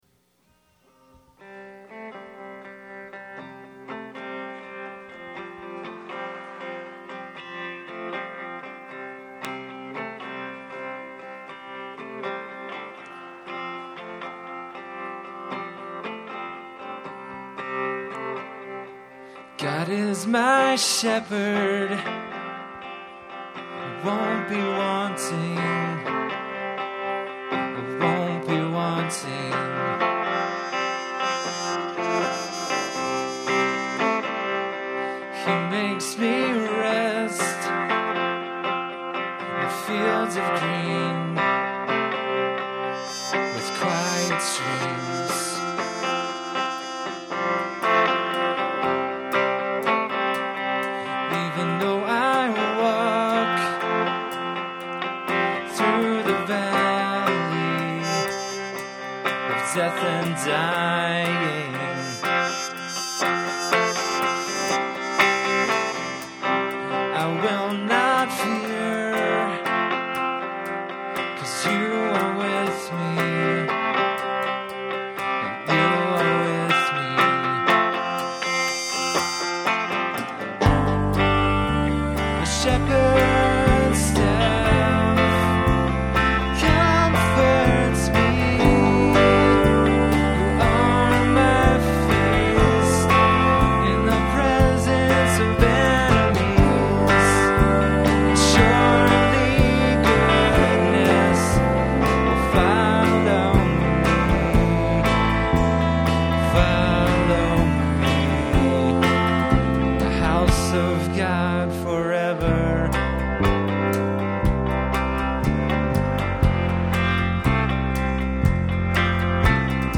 Performed live at Terra Nova - Troy on 5/17/09.